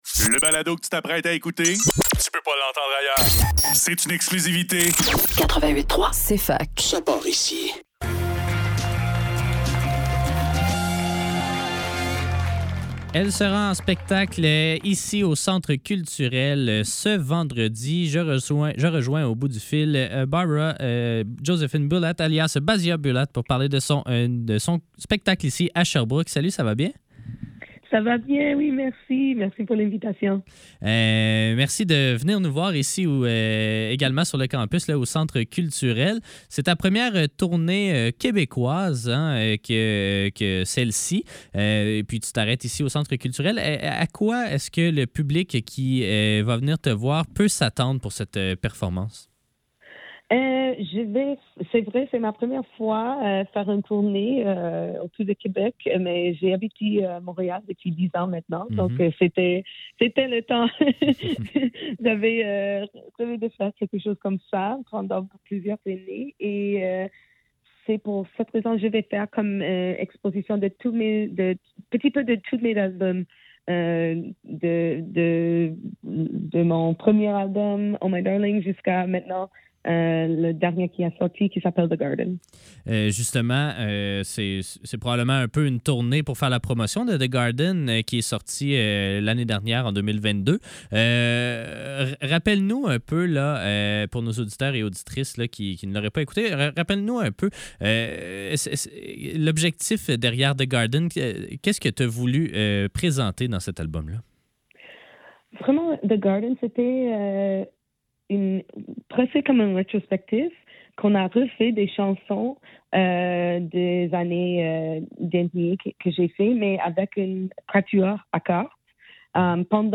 Faudrait que tout l'monde en parle - Entrevue